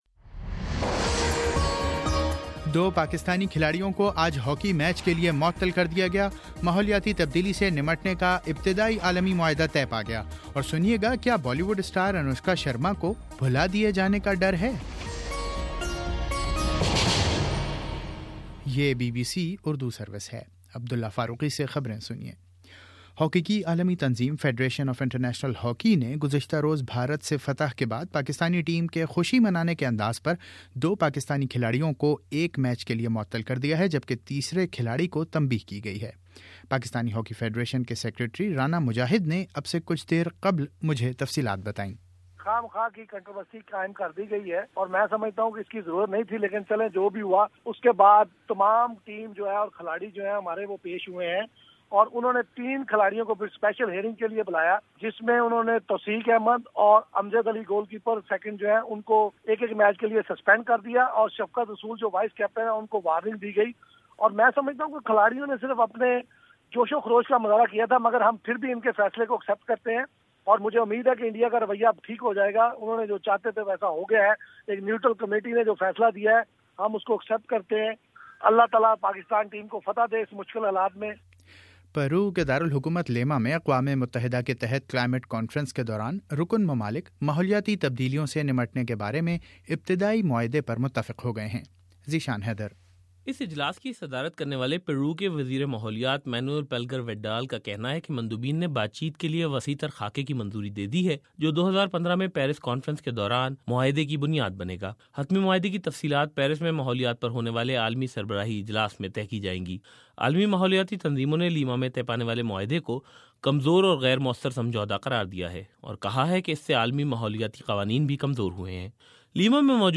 دسمبر14: شام چھ بجے کا نیوز بُلیٹن